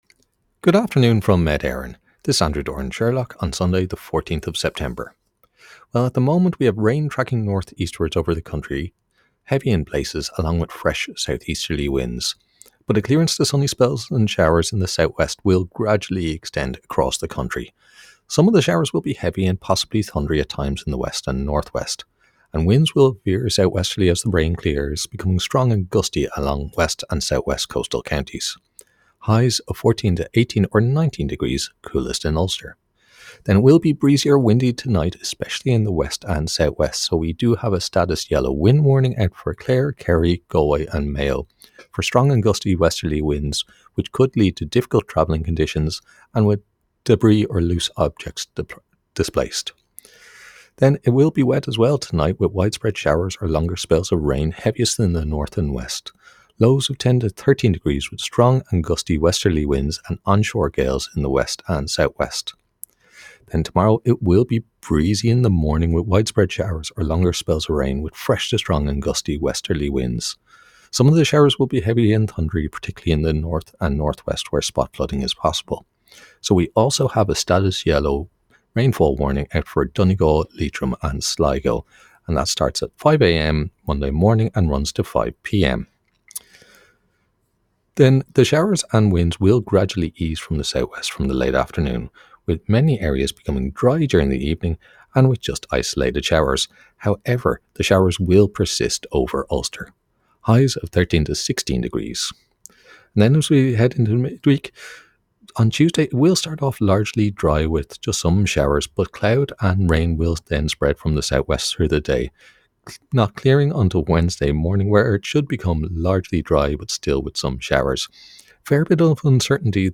Weather Forecast from Met Éireann